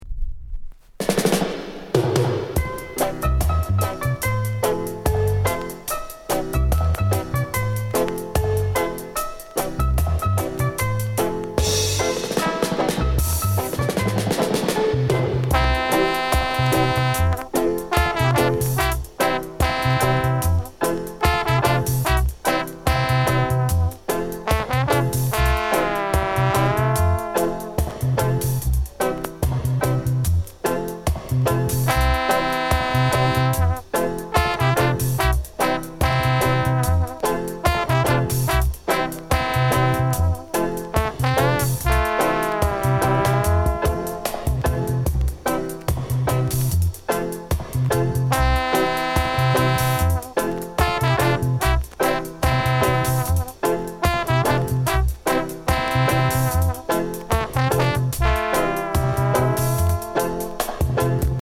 HORN INST